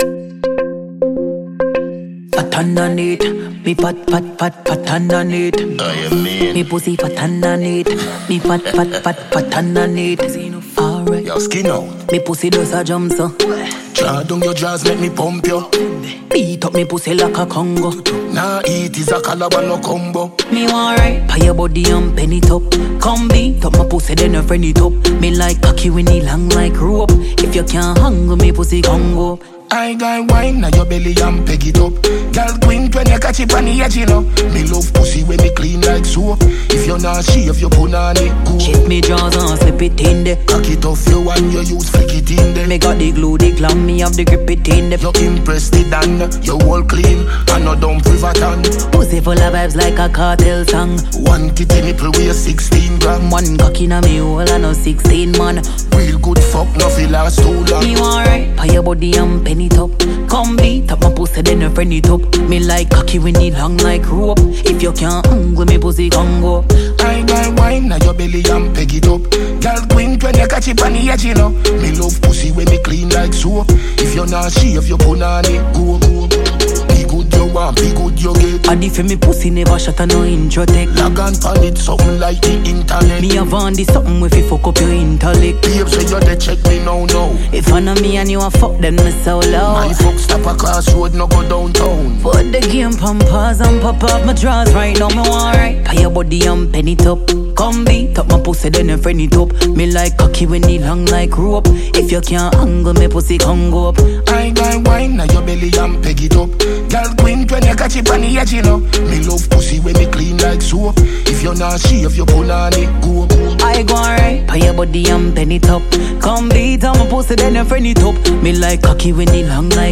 Dancehall/HiphopMusic
Jamaican female singer
dancehall tune